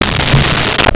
Creature Growl 02
Creature Growl 02.wav